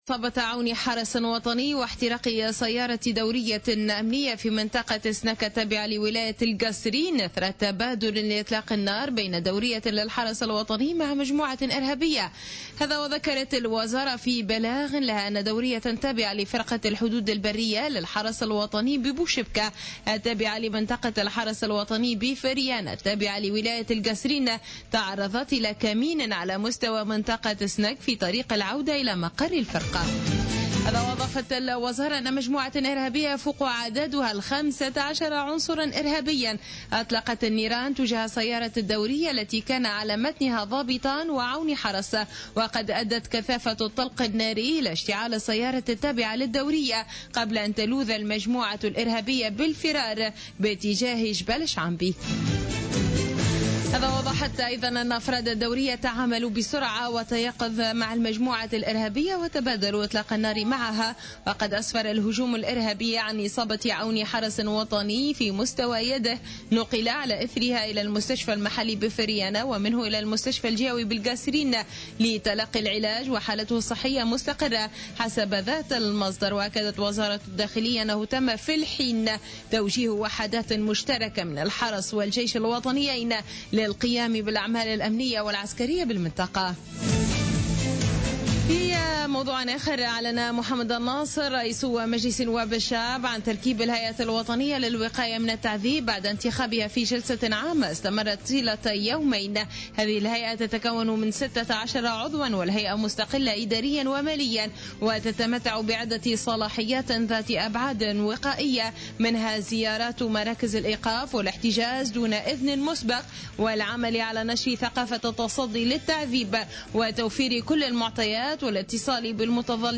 نشرة أخبار منتصف الليل ليوم الخميس 31 مارس 2016